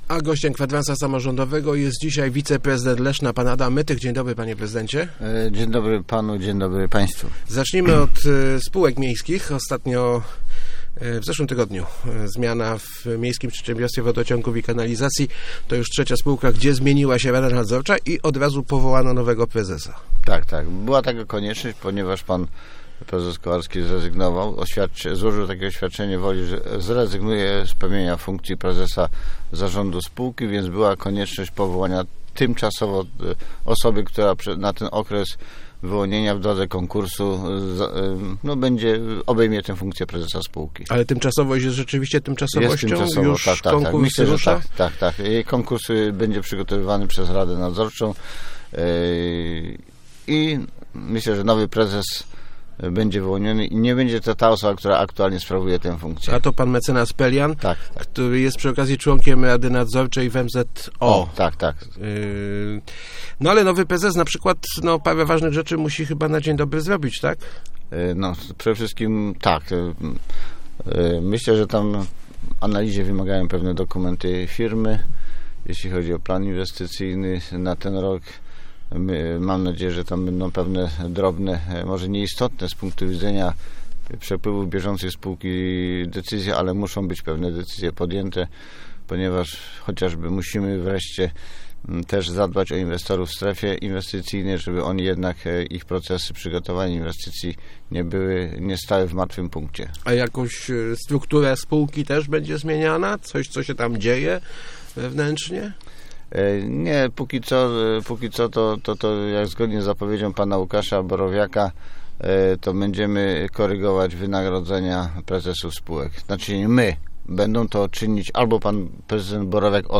Gościem Kwadransa był wiceprezydent Adam Mytych.